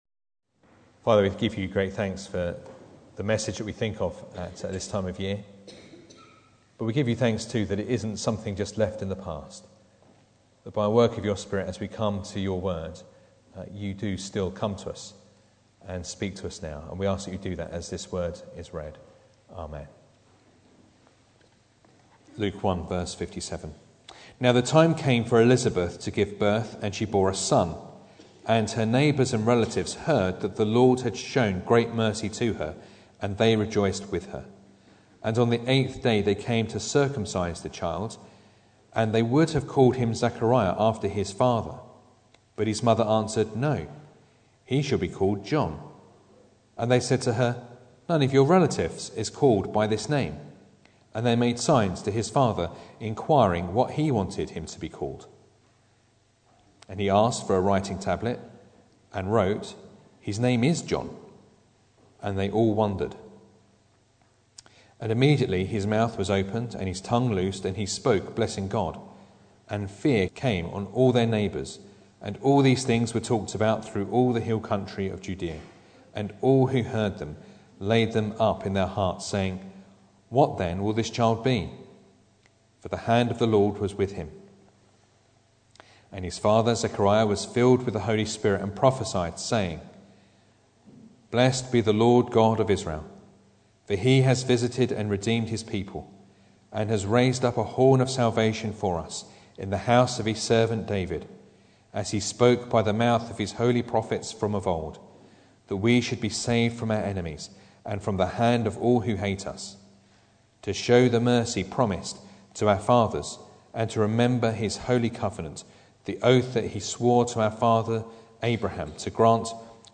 Luke 1:57-80 Service Type: Sunday Morning Bible Text